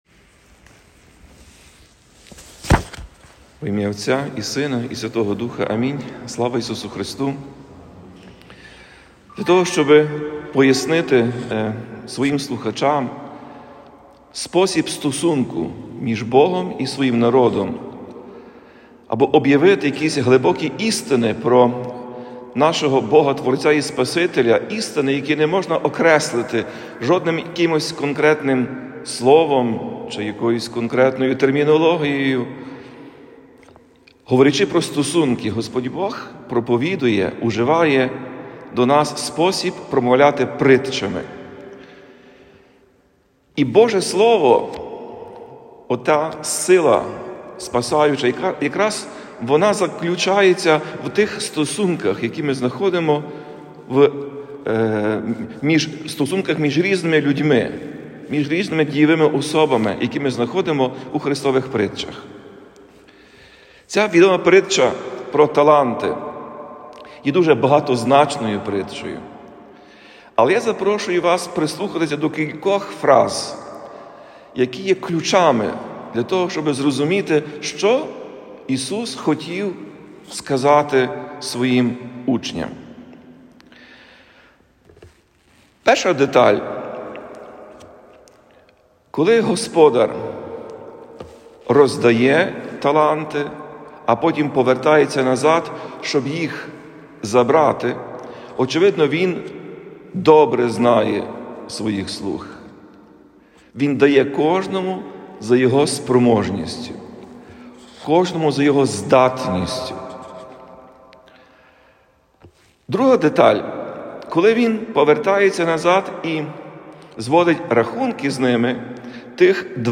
Проповідь Блаженнішого Святослава у 16-ту неділю після Зіслання Святого Духа